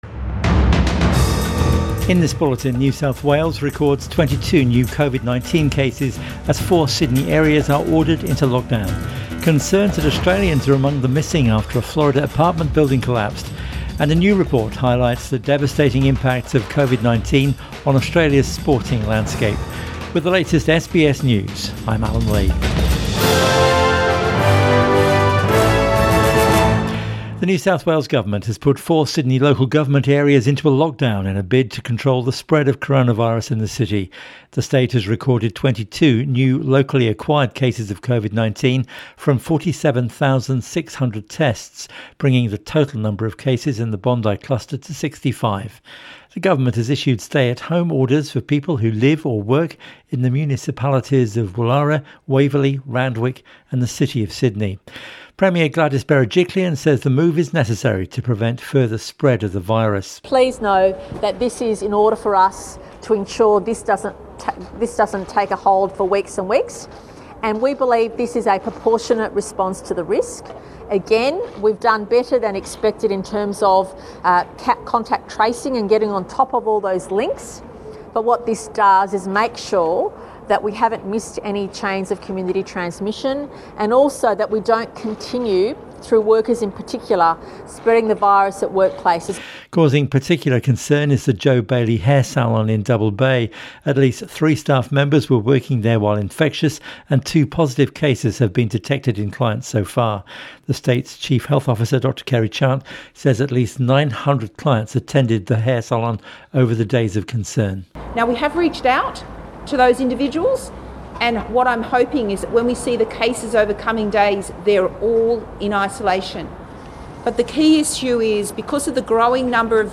PM Bulletin 25 June 2021